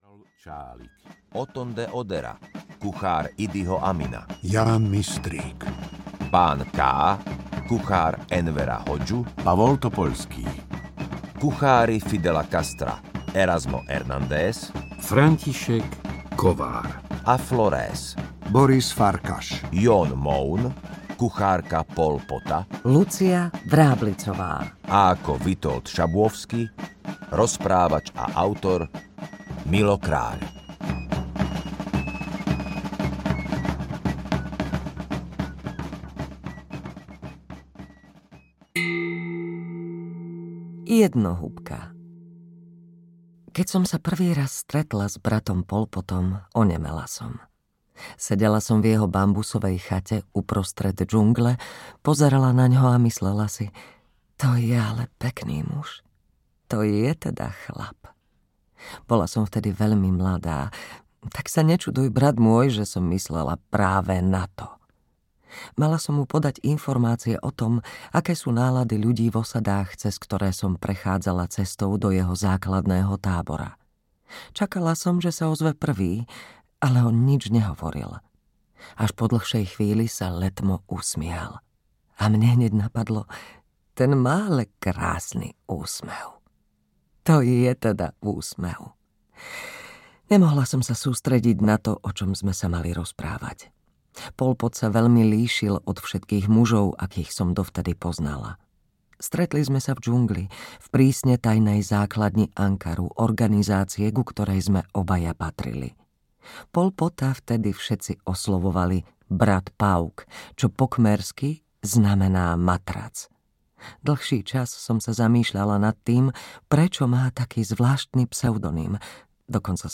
Ako nakŕmiť diktátora audiokniha
Ukázka z knihy